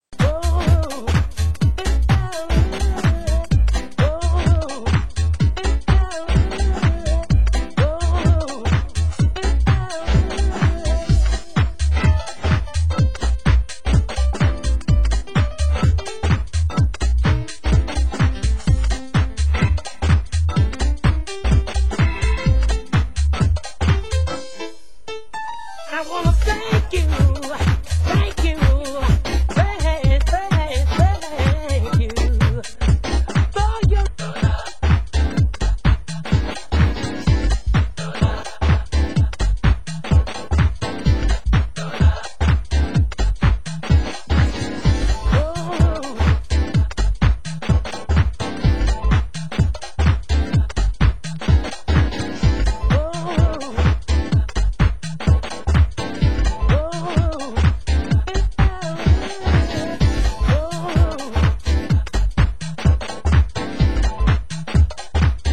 Format: Vinyl 12 Inch
Genre: UK Garage